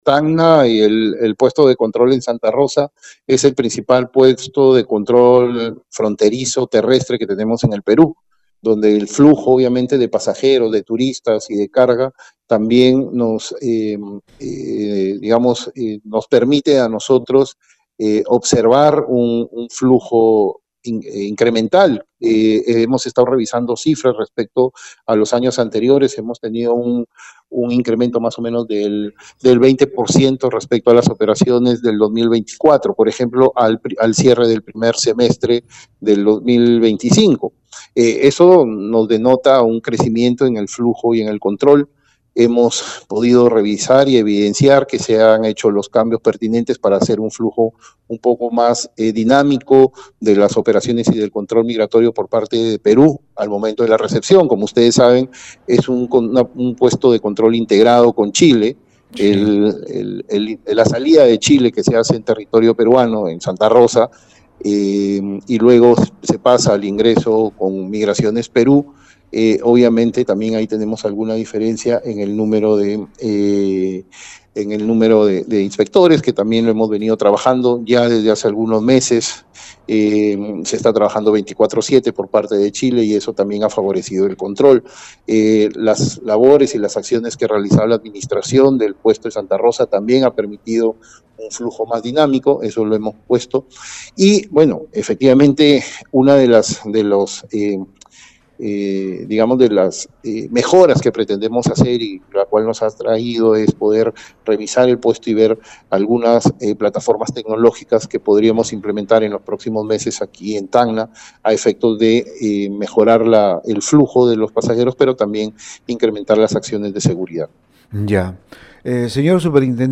El superintendente nacional de Migraciones, Armando García Chunga, ha realizado una visita a la oficina de Migraciones en Ilo y conversó con Radio Uno, destacando a la vez el incremento del flujo de pasajeros en el puesto de control fronterizo de Santa Rosa de Tacna.
4-armando-garcia-chunga-superintendente-nacional-de-migraciones.mp3